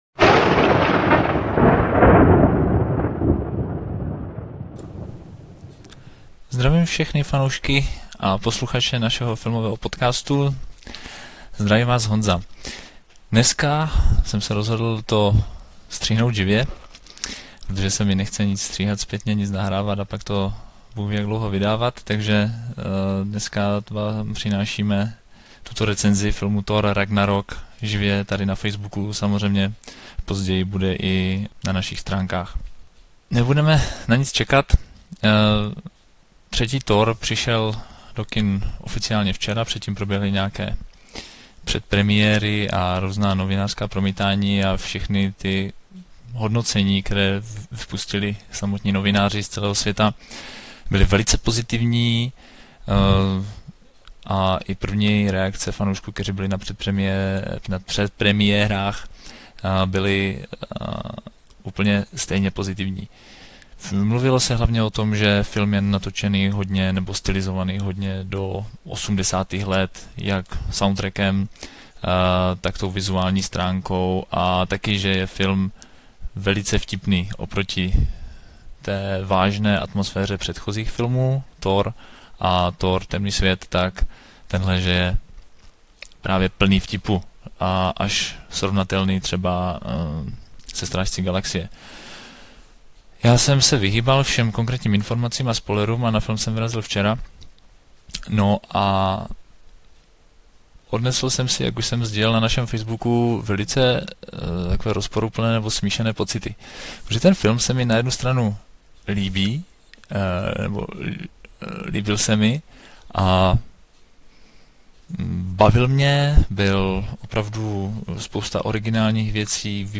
Svůj názor jsem vypustil v živém vysílání na našem Facebooku, takže tady to máte vše.